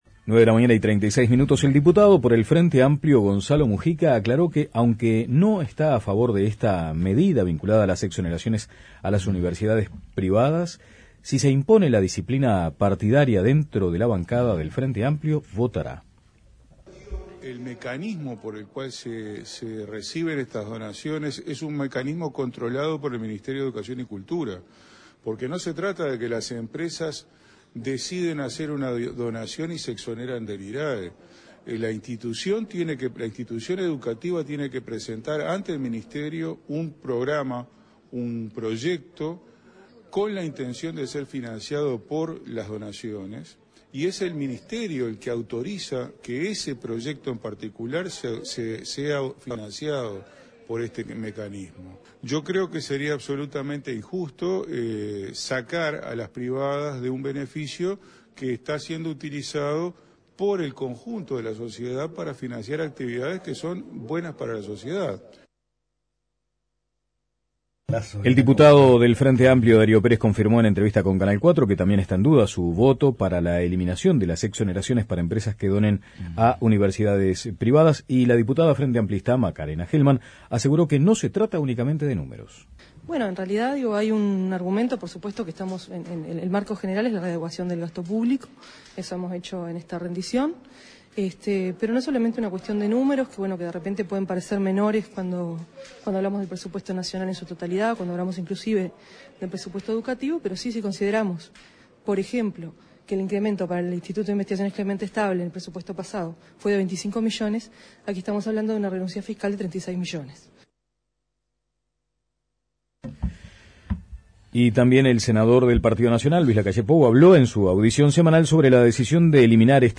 Debate sobre educación pública y privada.
El rector de la Universidad de la República participó especialmente en esta mesa, donde el debate giró en torno a la educación pública y privada. Además, los integrantes hablaron sobre los resultados de las elecciones internas del Frente Amplio.